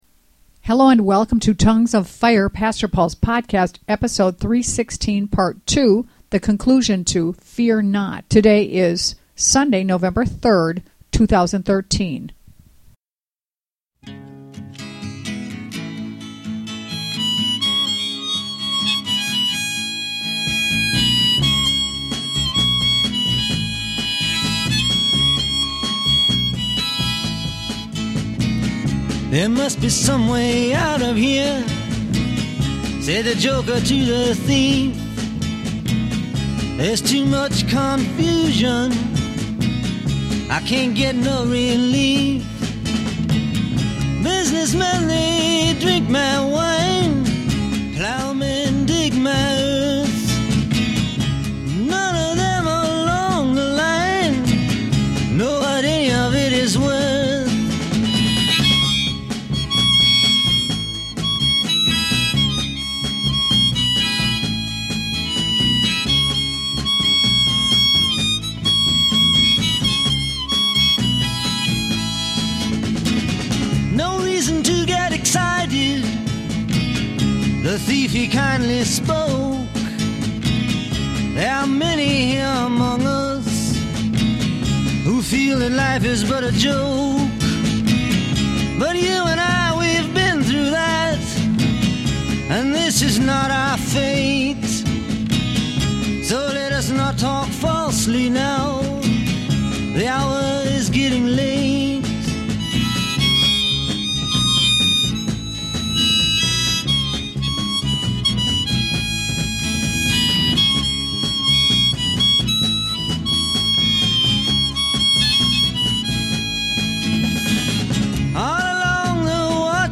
Originally Recorded November 15 , 1987